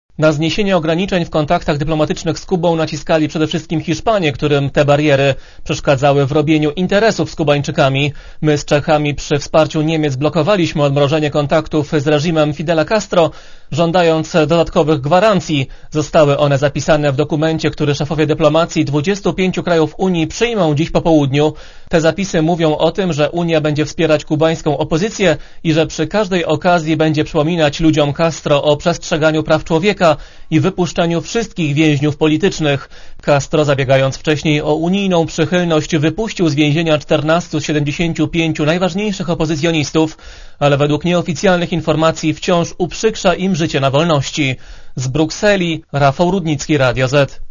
Relacja reportera Radia ZET Unia zniesie na pół roku, do czerwca, ograniczenia w stosunkach z Kubą, dotyczące wizyt na wysokim szczeblu.